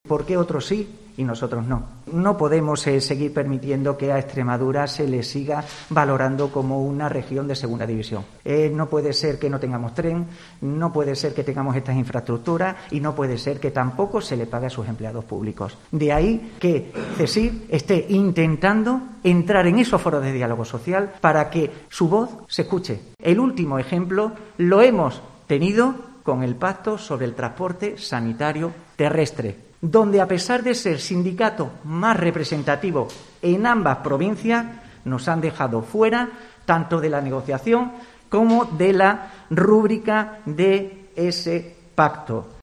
Este anuncio lo ha realizado en rueda de prensa este lunes en Mérida